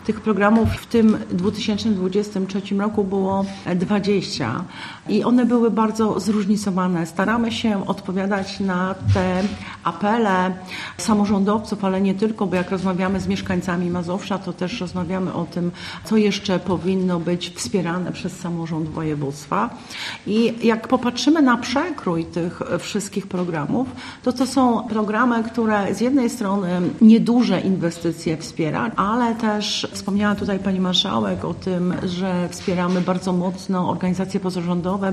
Członkini zarządu województwa, Janina Ewa Orzełowska dodała, że realizacja programów odbywała się zgodnie z planem: